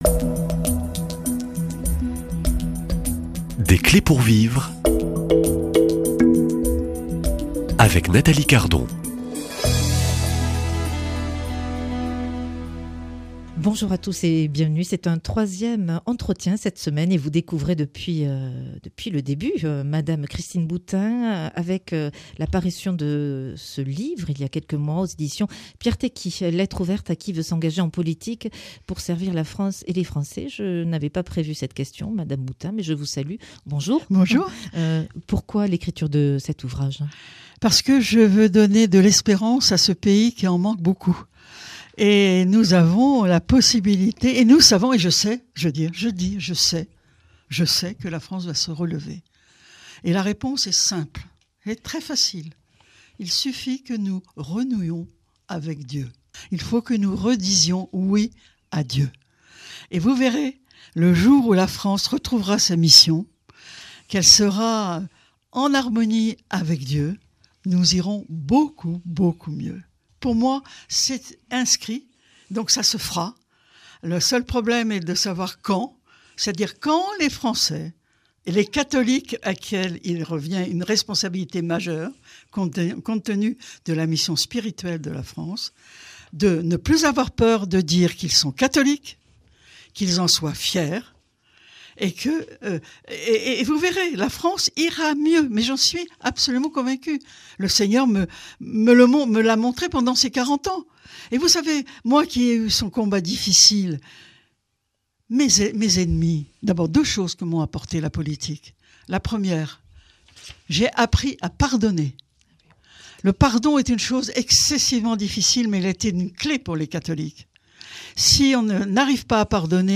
Dans un pays marqué par la laïcité, comment vivre pleinement sa foi dans l’espace politique, sans se cacher, sans imposer ? Christine Boutin nous partage ce qu’elle a appris au fil des années. Entre incompréhensions, critiques, et fidélité intérieure, elle témoigne d’un engagement enraciné dans l’Évangile.